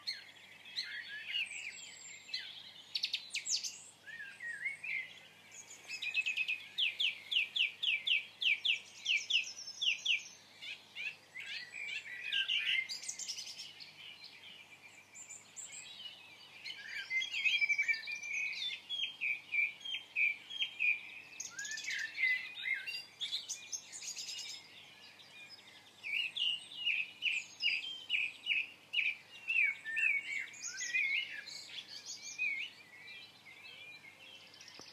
evening-birdsong-3.m4a